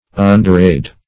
Underaid \Un`der*aid"\